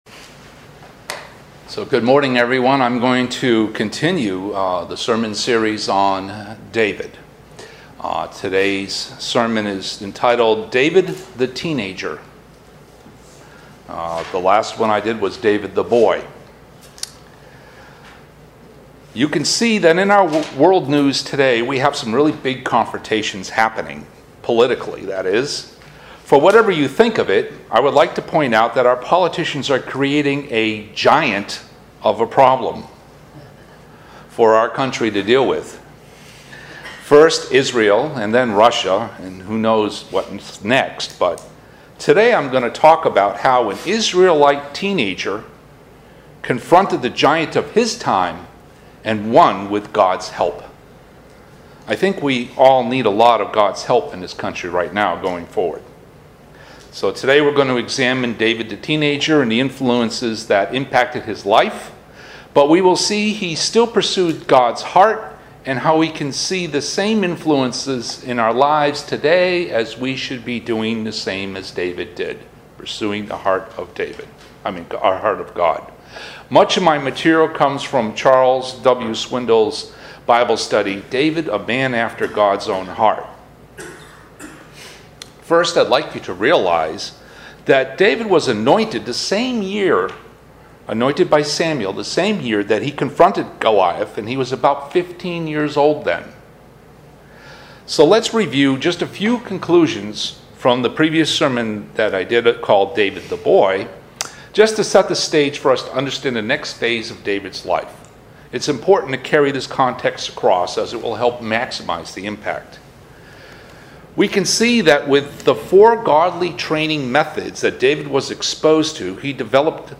This sermon discusses how David, an Israelite teenager, confronted the giant of his time and won with God's help. We too wrestle our own giants in the low points of our lives and lessons from David can help us as well.